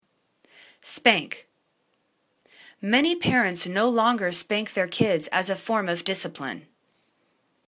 spank     /spangk/    v